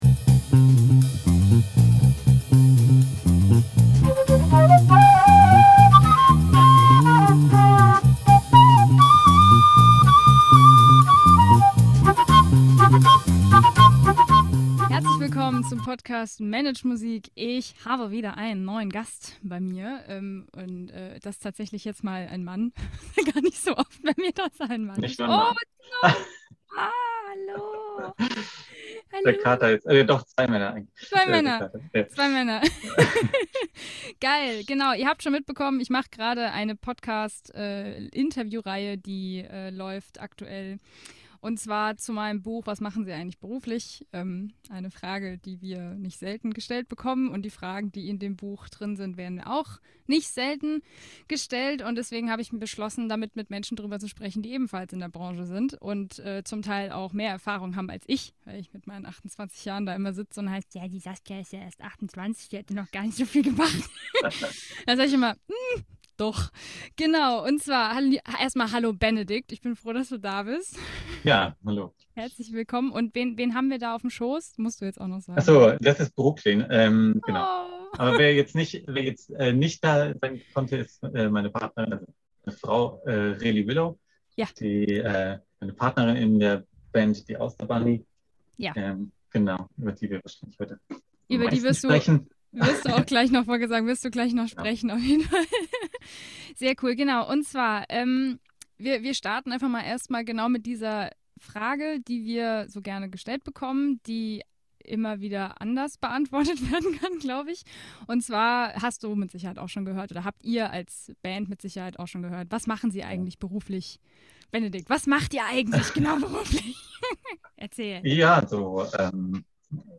interview-mit-die-auster-bunny-mmp.mp3